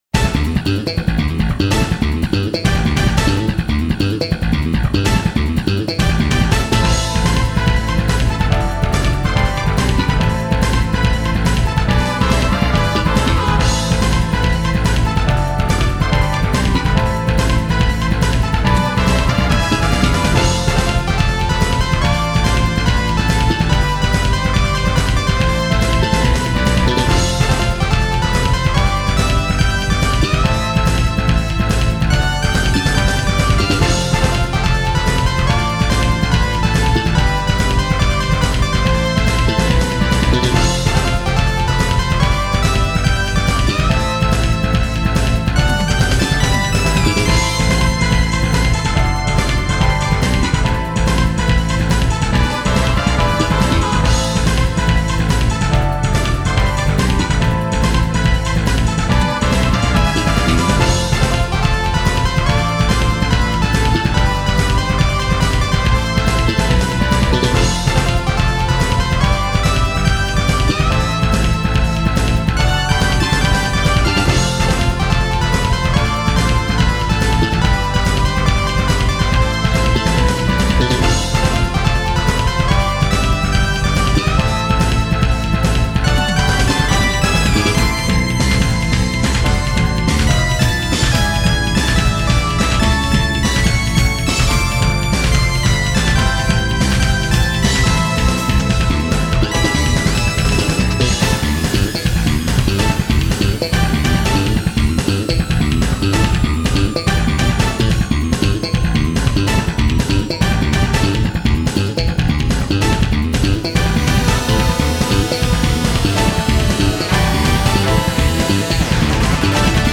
なお、掲載している音源には、EQとコンプレッサー等で若干のマスタリング処理を施してあります。
この頃になると、フュージョン系の音楽を明確に志向し始め、ゲームミュージックからの影響を織り交ぜながら、自分なりのバンドサウンドを打ち込みで表現しようと試みていました。
特にこだわっていたのがベースで、ベーシストの鳴瀬喜博氏によるチョッパーベースに心酔していた当時の私は、多くの作品でチョッパーベースを登場させており、この曲でも冒頭からチョッパーのフレーズを響かせています。